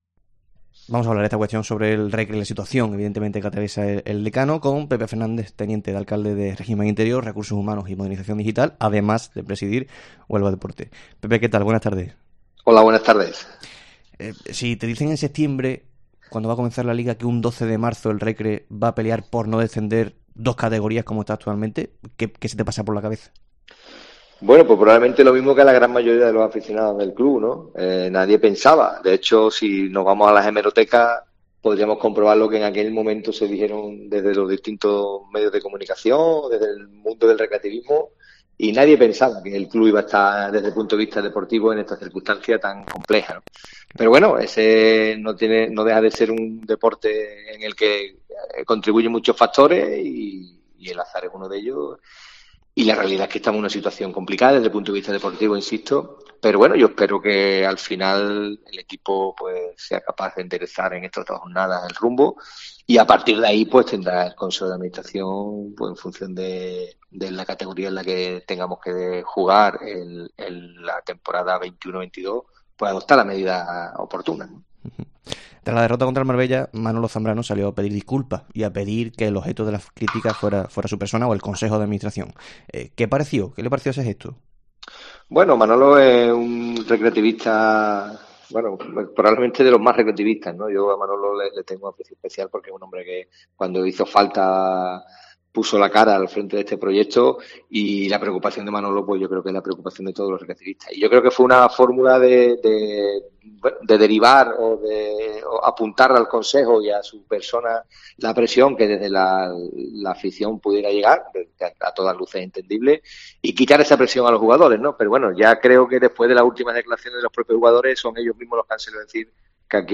El teniente de alcalde de Régimen Interior, Recursos Humanos y Modernización Digital, Pepe Fernández, ha atendido a COPE Huelva para anailzar la actual...